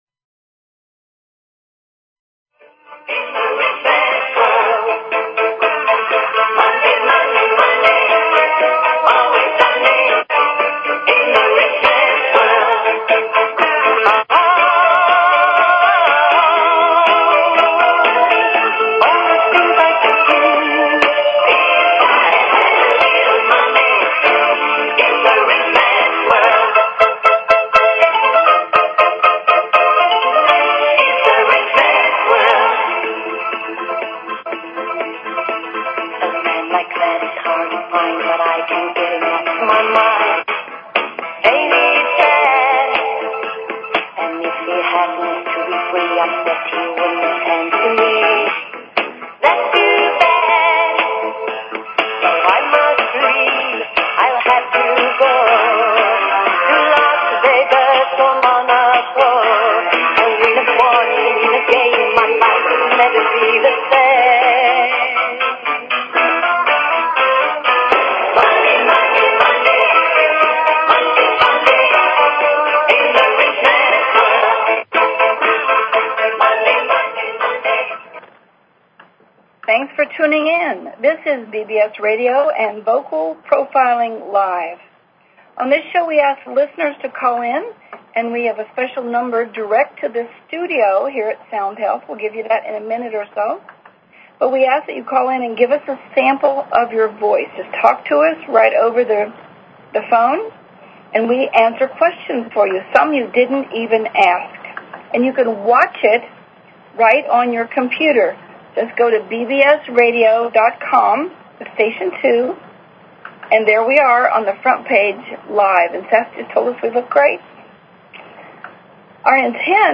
Talk Show Episode, Audio Podcast, Vocal_Profiling_Live and Courtesy of BBS Radio on , show guests , about , categorized as
We were still doing voice prints as we signed off for the evening.